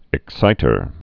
(ĭk-sītər)